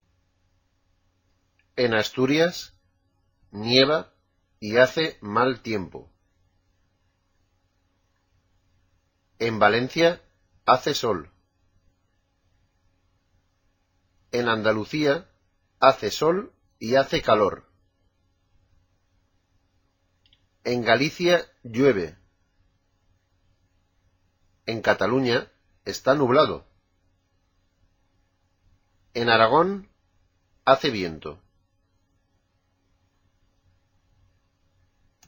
Escucha al hombre del tiempo y escribe cual es el tiempo que hace en las siguientes regiones españolas.